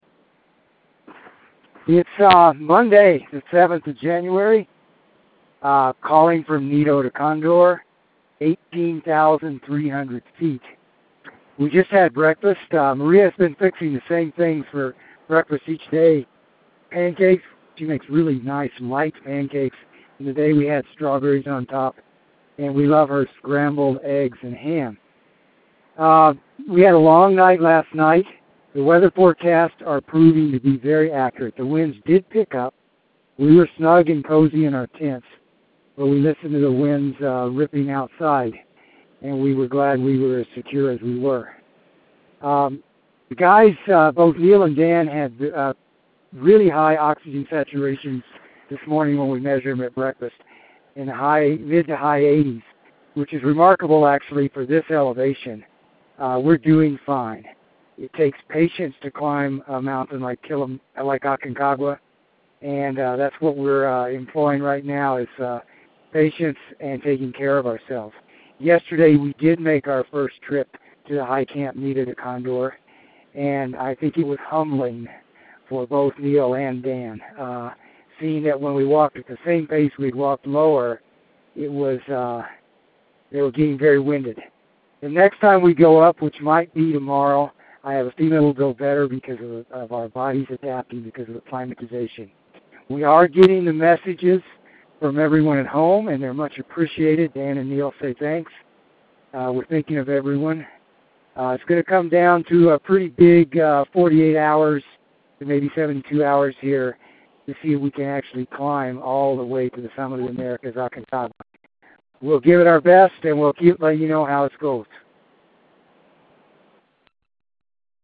Aconcagua Expedition Dispatch